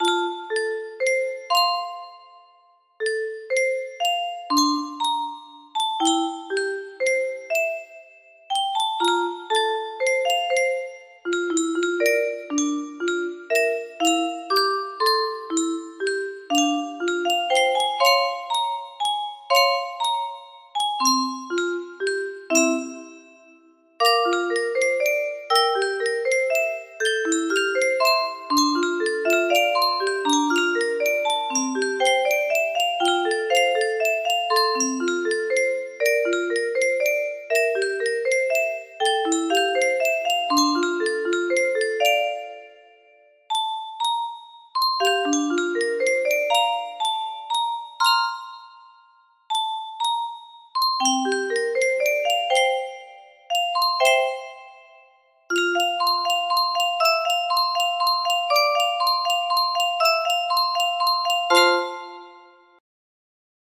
Grand Illusions 30 (F scale)